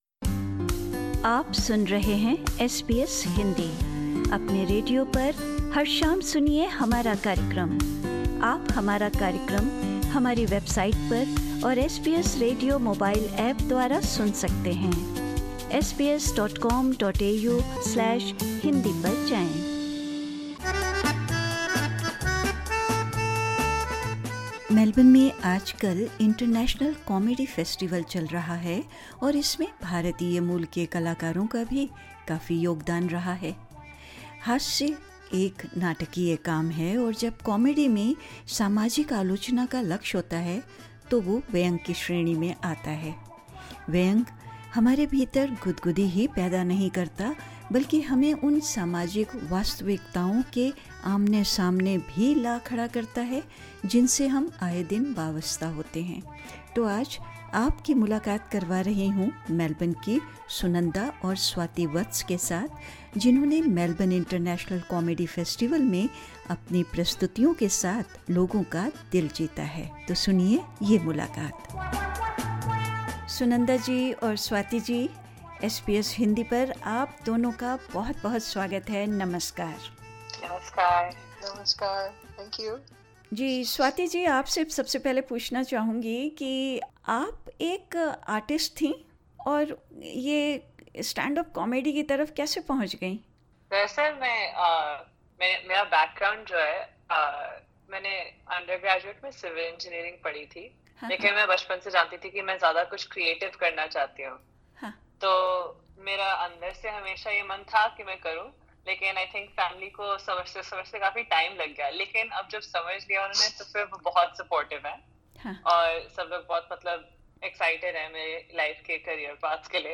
Speaking with SBS Hindi, these Melbourne artists share how their identities, and the passion for comedy have broken down the barriers to acceptance.